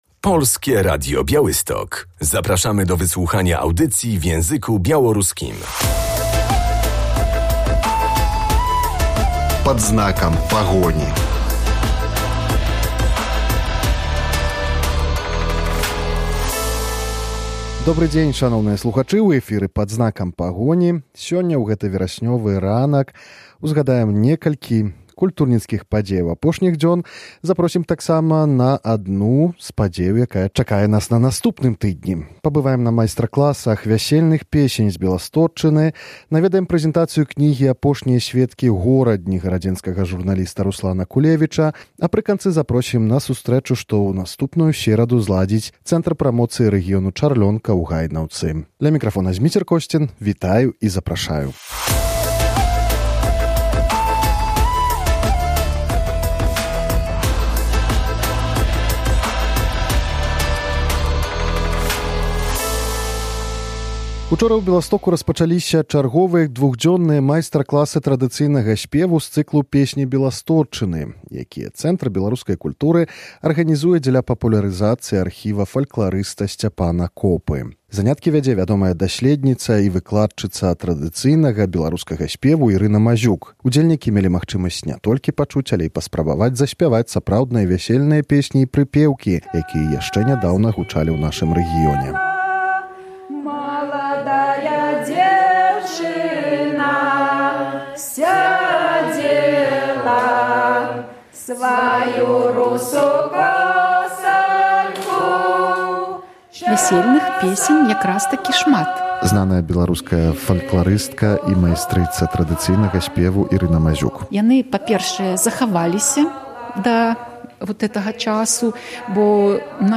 Była to okazja, by nauczyć się pieśni śpiewanych na podlaskich weselach jeszcze 40 lat temu. W sobotę (20.09) w Białymstoku rozpoczęły się warsztaty tradycyjnego śpiewu z cyklu „Pieśni Białostocczyzny”.